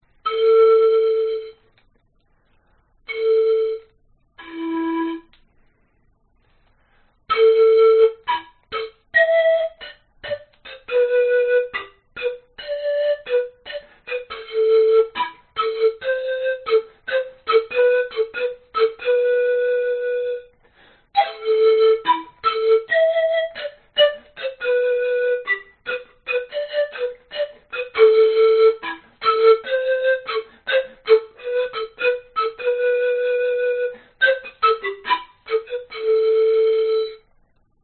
标签： 长笛 板笛 传统
声道单声道